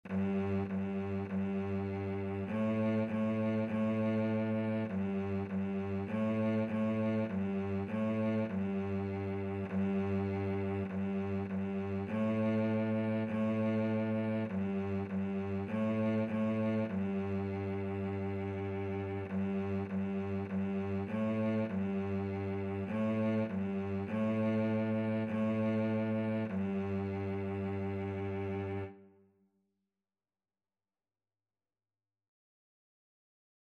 4/4 (View more 4/4 Music)
G3-A3
Beginners Level: Recommended for Beginners
Cello  (View more Beginners Cello Music)
Classical (View more Classical Cello Music)